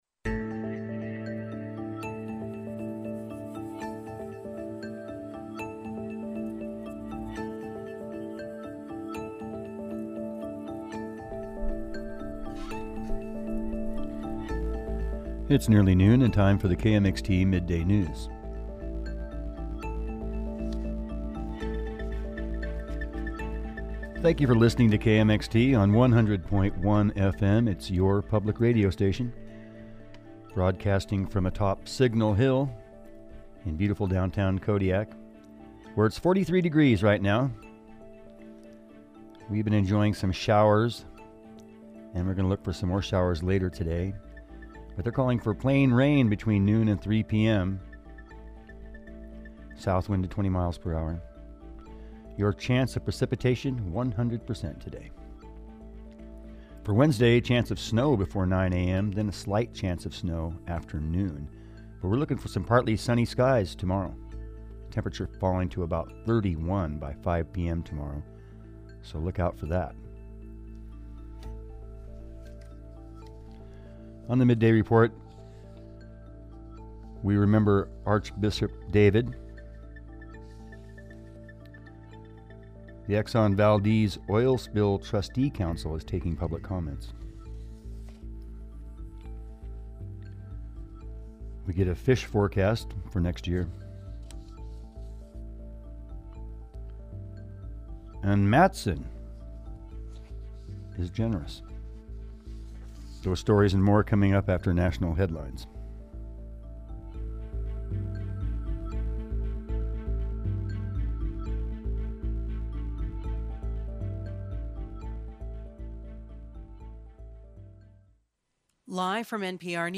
Newscast–Tuesday, December 1, 2020